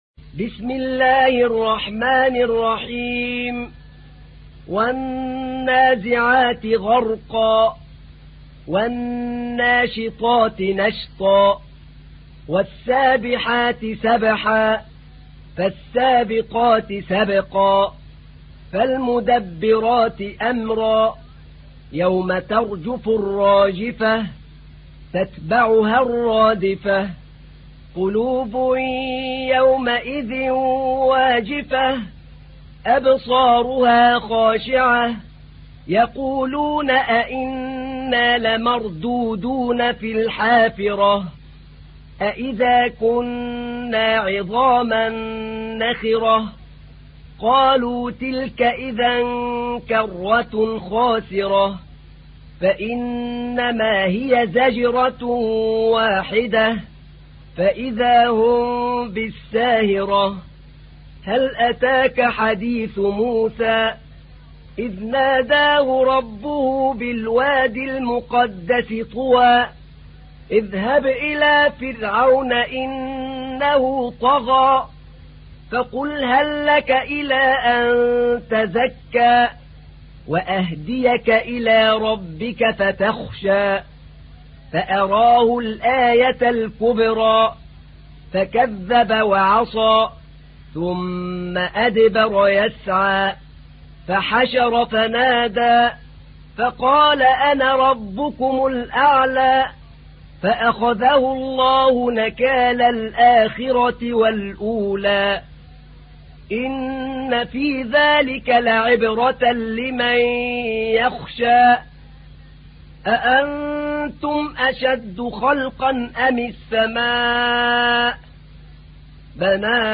تحميل : 79. سورة النازعات / القارئ أحمد نعينع / القرآن الكريم / موقع يا حسين